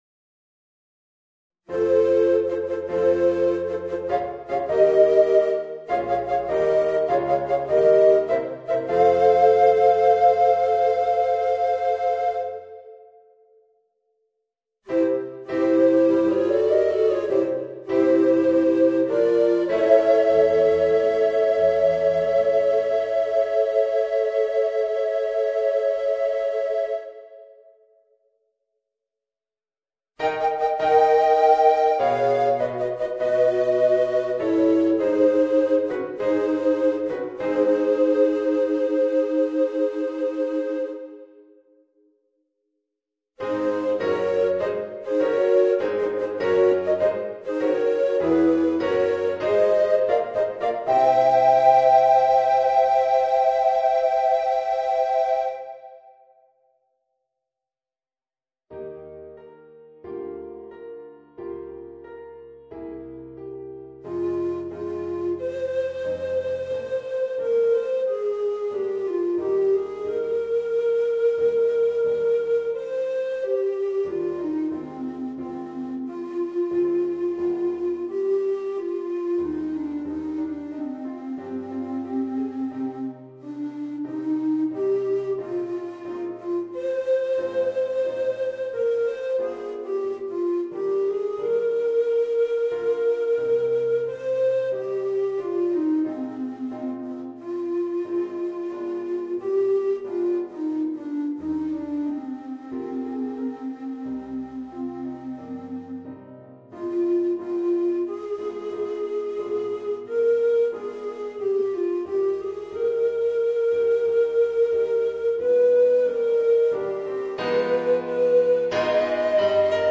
Voicing: Tenor Recorder and Organ